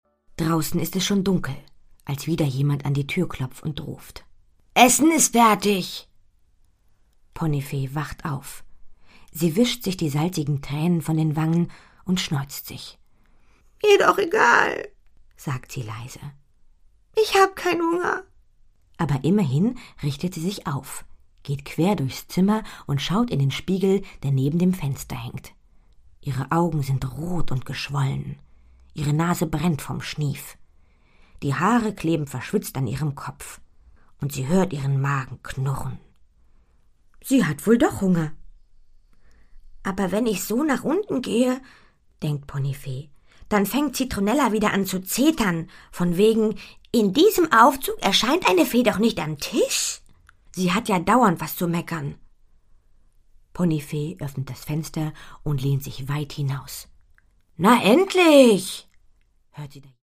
Produkttyp: Hörbuch-Download
Fassung: ungekürzte Fassung